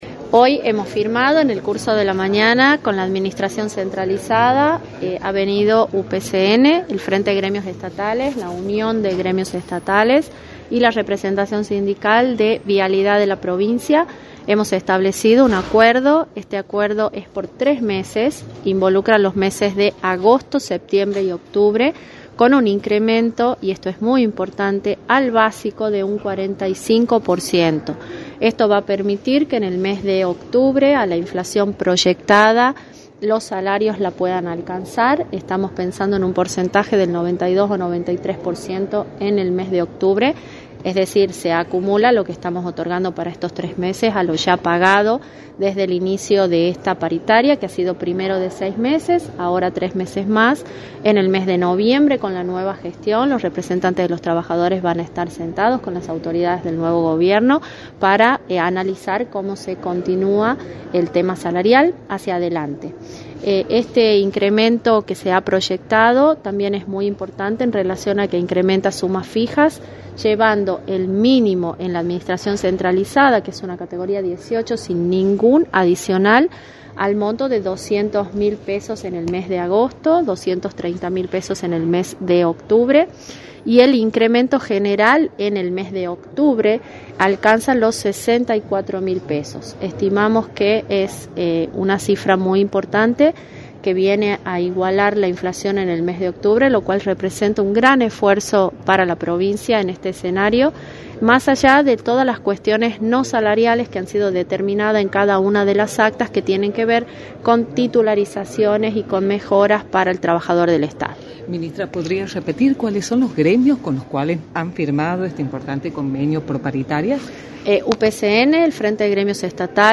Carolina Vargas Aignasse, Ministra de Gobierno y Justicia, informó en Radio del Plata Tucumán, por la 93.9, las repercusiones de las reuniones que se llevaron a cabo con el Frente Gremial Estatal y que se desarrollarán durante la semana, y por otro lado, analizó los resultados de las PASO y el triunfo de Javier Milei.
“Hemos establecido un acuerdo, un acuerdo que abarca los meses de agosto, septiembre y octubre y que implica un incremento al básico de un 45%, esto va a permitir que los salarios puedan alcanzar a la inflación proyectada y en el mes de noviembre, los gremios van a reunirse con las nuevas autoridades” señaló Carolina Vargas Aignasse en entrevista para «La Mañana del Plata» por la 93.9.